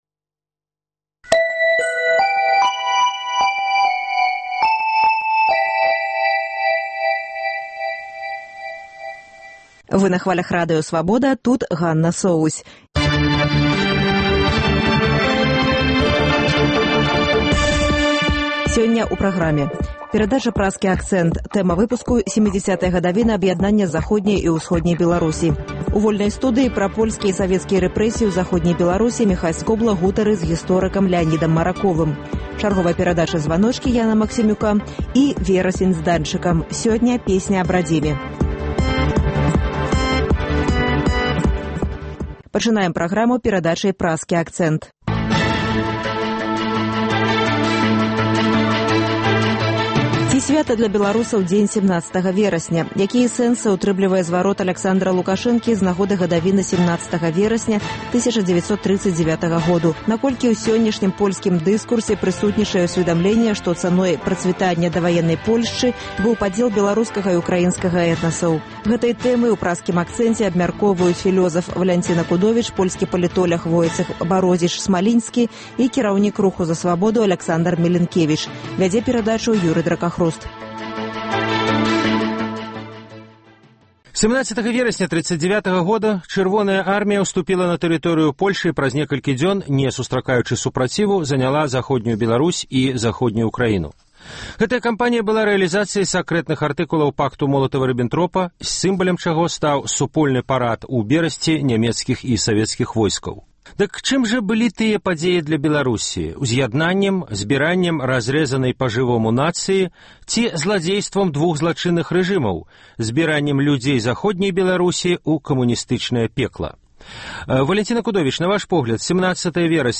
Вядзе перадачу